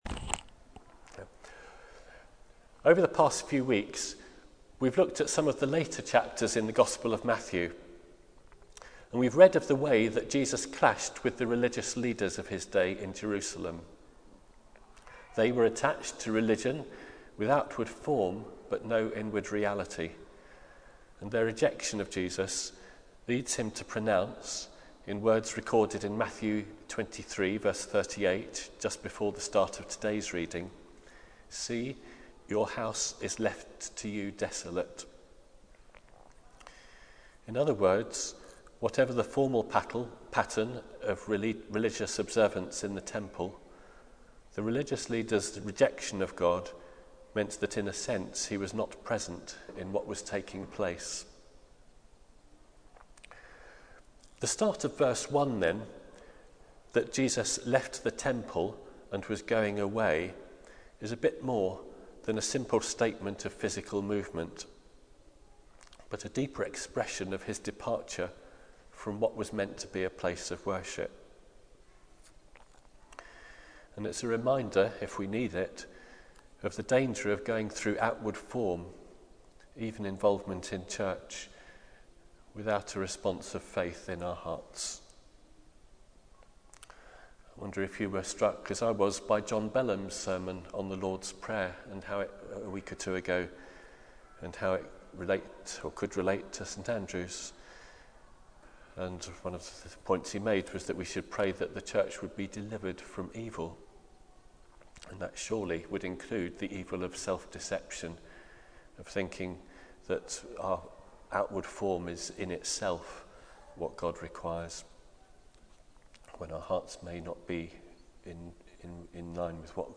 Agnostics Anonymous Theme: Isn't the end of the world just scaremongering? Sermon Search: